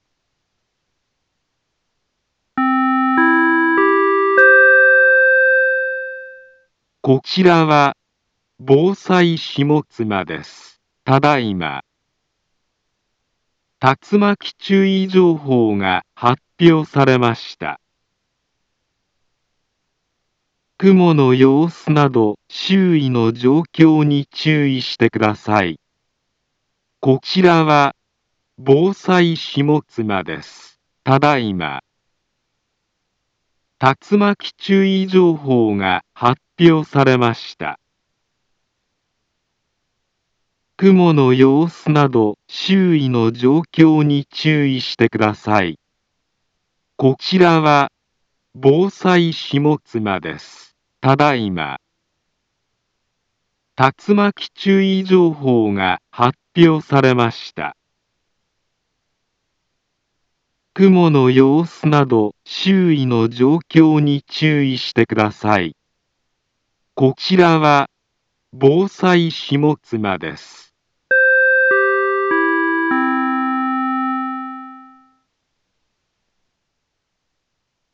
Back Home Ｊアラート情報 音声放送 再生 災害情報 カテゴリ：J-ALERT 登録日時：2023-07-13 20:49:49 インフォメーション：茨城県北部、南部は、竜巻などの激しい突風が発生しやすい気象状況になっています。